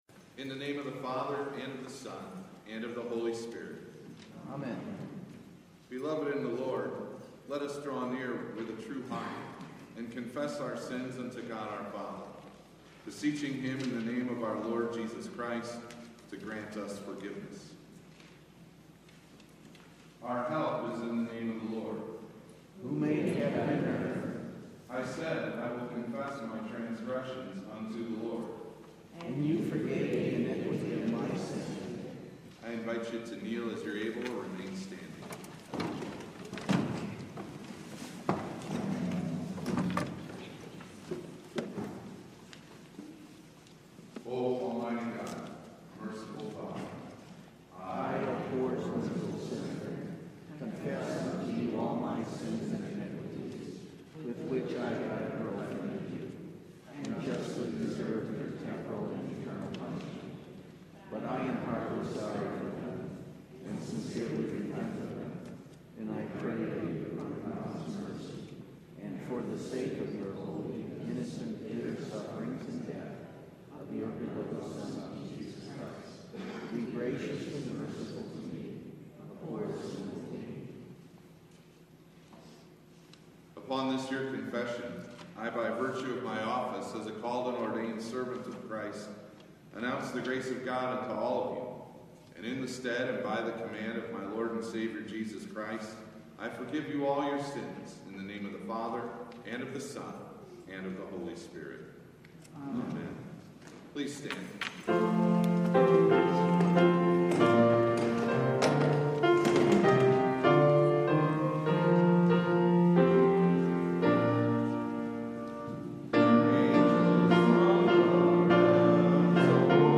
Dec 23 / Sat Blended – Never Broken – Lutheran Worship audio